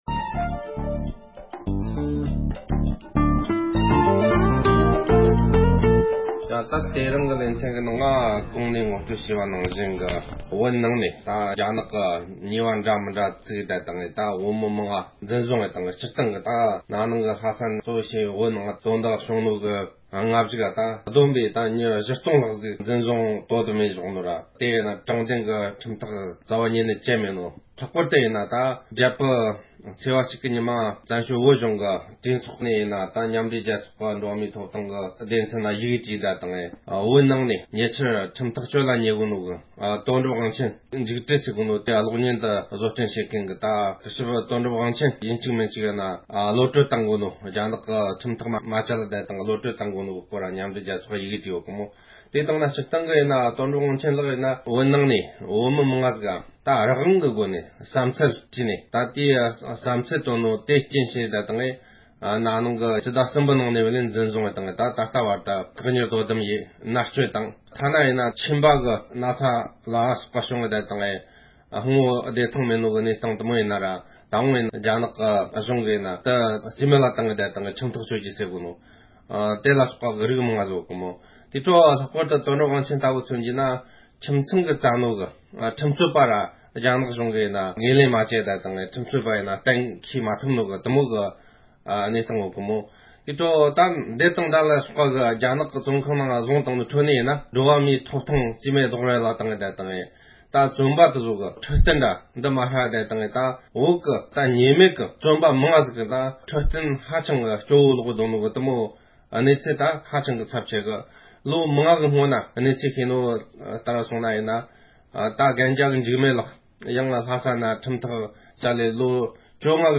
རྒྱ་ནག་གཞུང་གིས་བོད་ནང་གི་བོད་མི་མང་པོ་ཞིག་ལ་དྲང་བདེན་མིན་པའི་ཐོག་ནས་ཁྲིམས་ཐག་གཅོད་བཞིན་ཡོད་པའི་སྐོར་གྱི་དཔྱད་གཏམ།